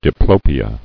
[di·plo·pi·a]